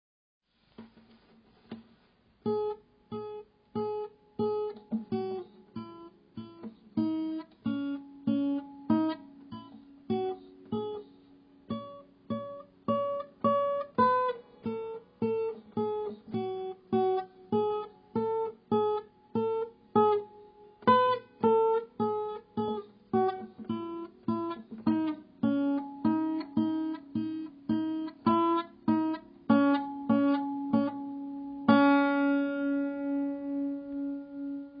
I play guiter today, too.